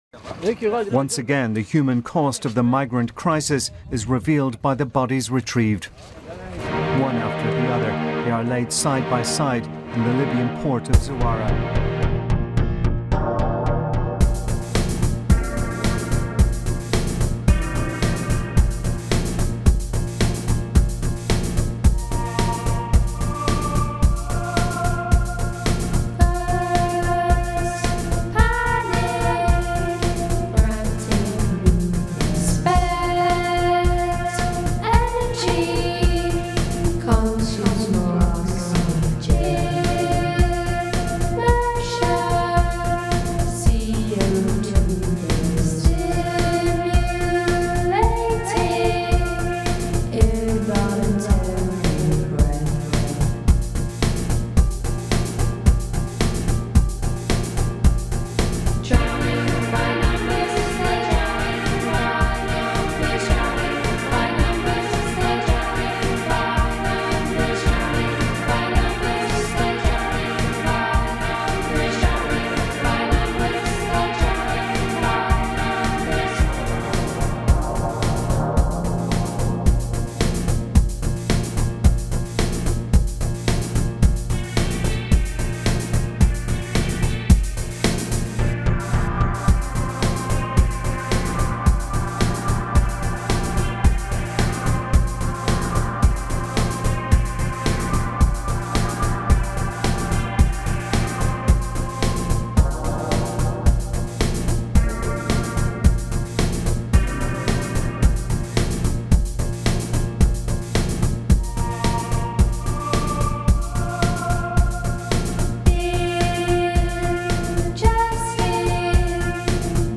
recorded at the garage, corsham